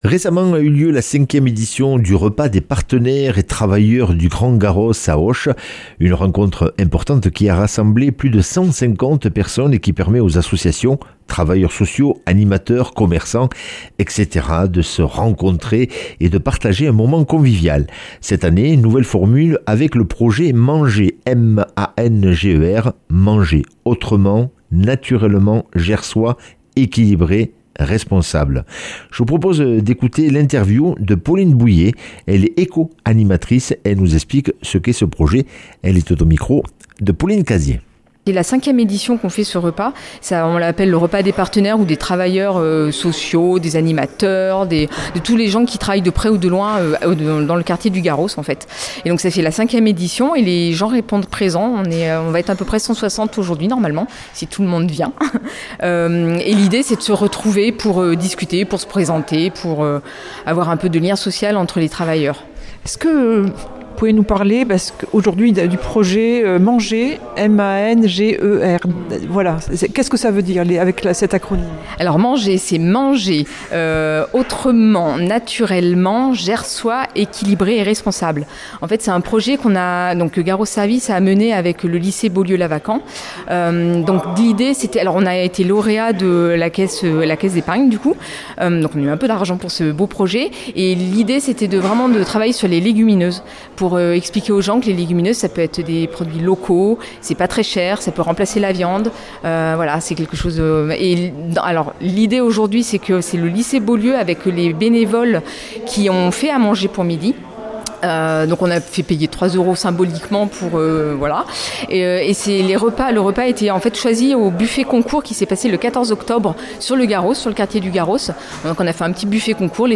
mercredi 17 décembre 2025 Interview et reportage Durée 10 min